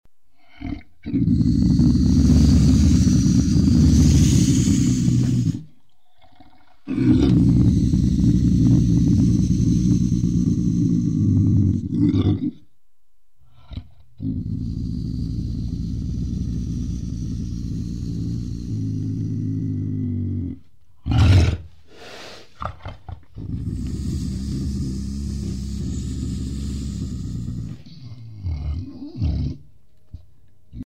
Звуки рыси
Угрожающий рык